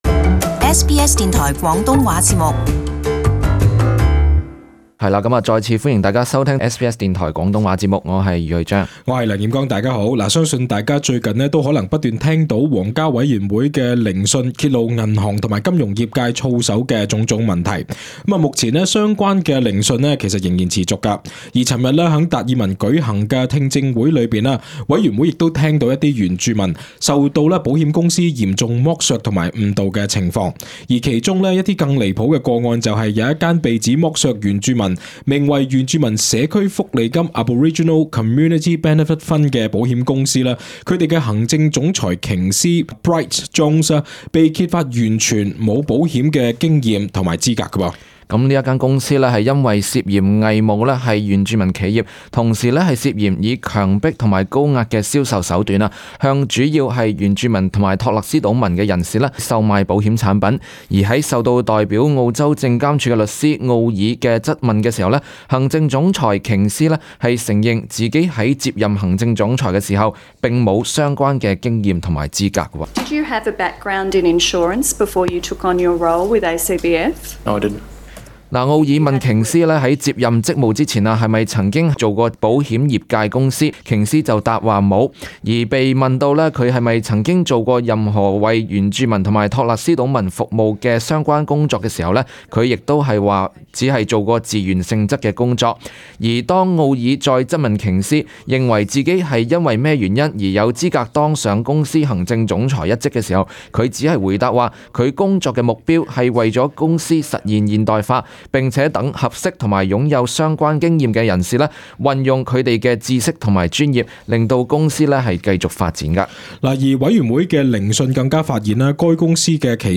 【时事报导】原住民受保险公司严重剥削